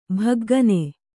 ♪ bhaggane